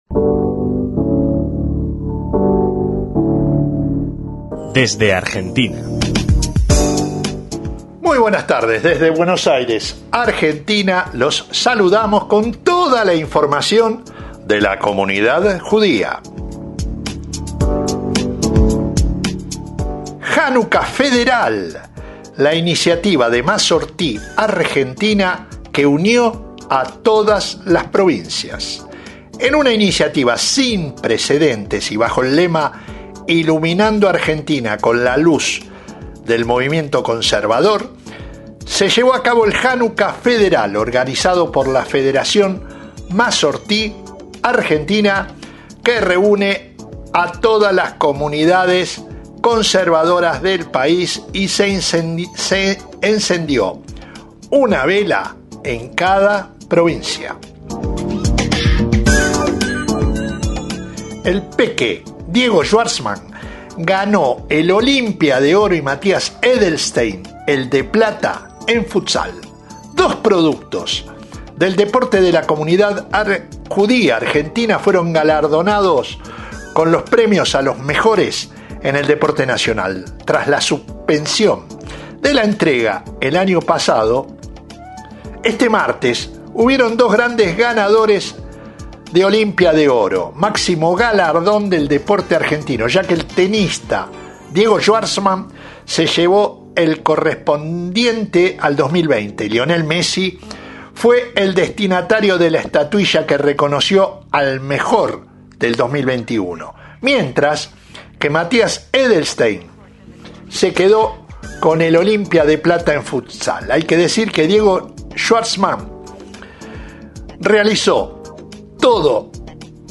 DESDE ARGENTINA, CON VIS A VIS